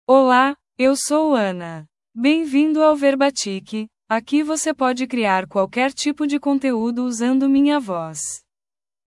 Anna — Female Portuguese (Brazil) AI Voice | TTS, Voice Cloning & Video | Verbatik AI
Anna is a female AI voice for Portuguese (Brazil).
Voice sample
Female
Anna delivers clear pronunciation with authentic Brazil Portuguese intonation, making your content sound professionally produced.